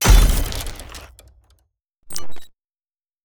SD_SFX_Player_Death_Fall.wav